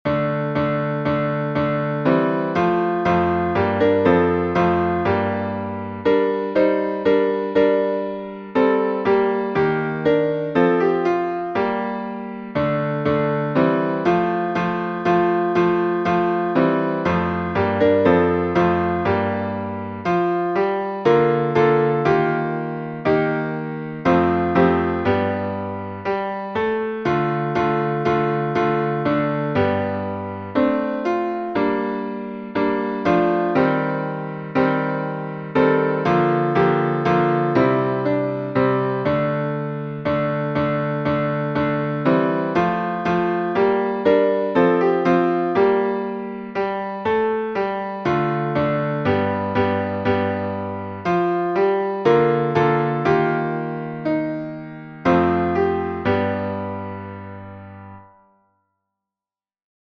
Григорианский хорал